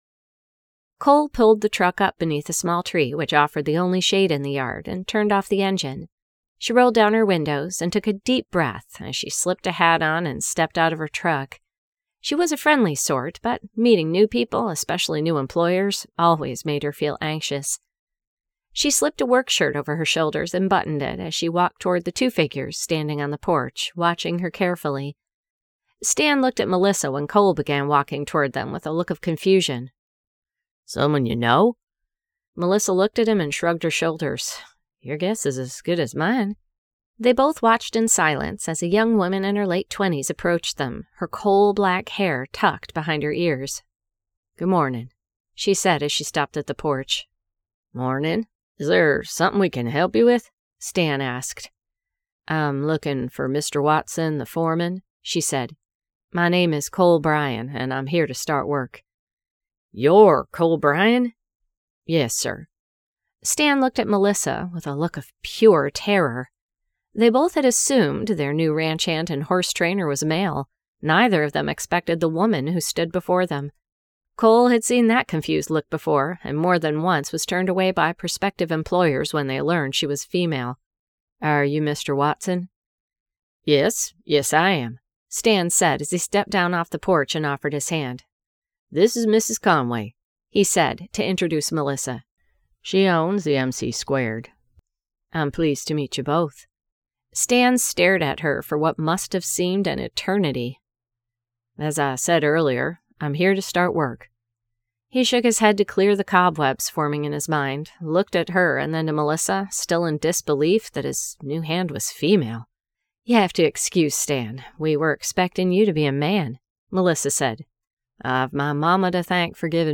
Cowgirl Up by Ali Spooner [Audiobook]